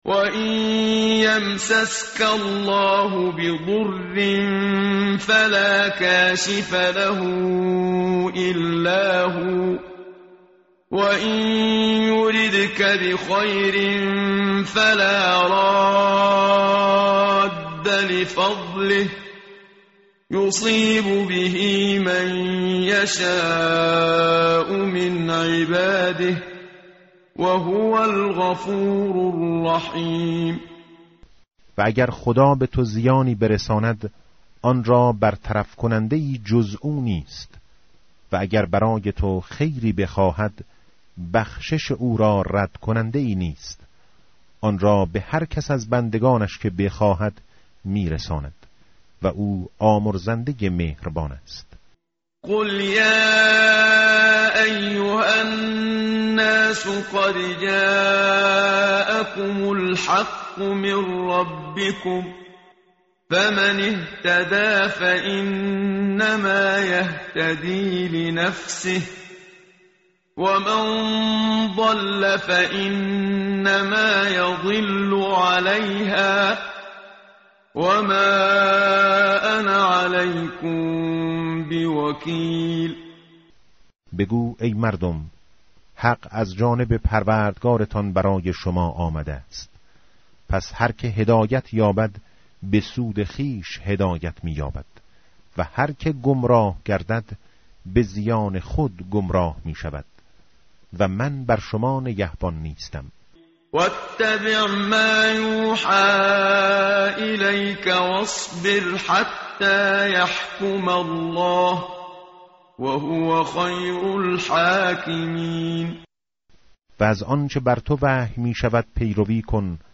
tartil_menshavi va tarjome_Page_221.mp3